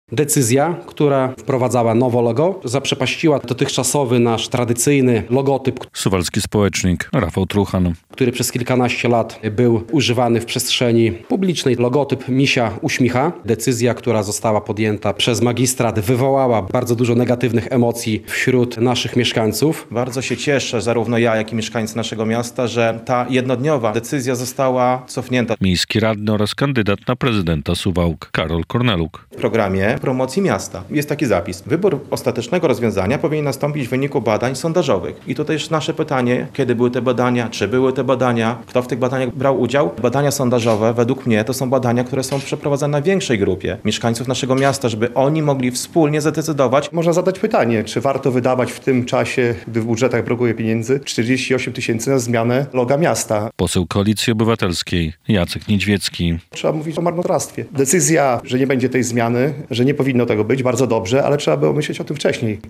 W piątek (23.02) na konferencji w biurze Platformy Obywatelskiej członkowie komitetu wyborczego „Wspólne Suwałki” krytykowali prezydenta, że podjął decyzję bez pytania mieszkańców o zdanie.